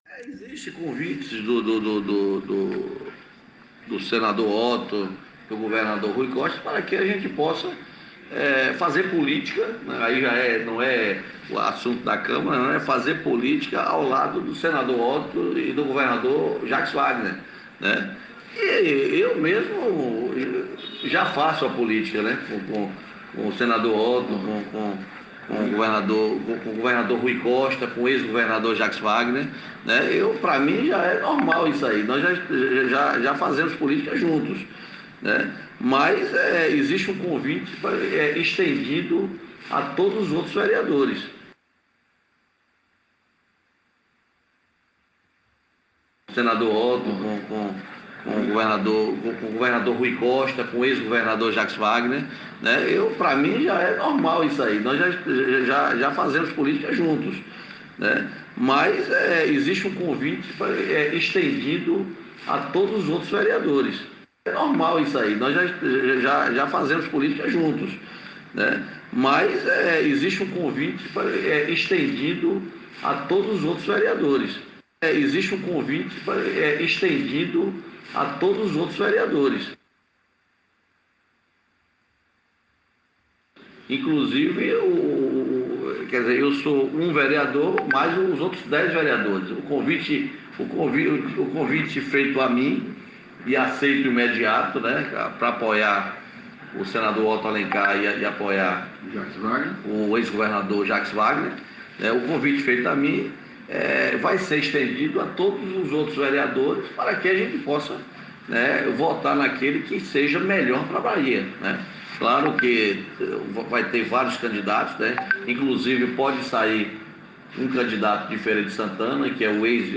Durante entrevista coletiva na amanhã de terça-feira (15), Fernando Torres, disse que está “fechado” com o senador Otto Alencar (PSD) e que este, está com Jaques Wagner (PT) e revelou que há um convite estendido os dez vereadores que lhe seguem na Câmara Municipal, para possam também aderir ao grupo do senador.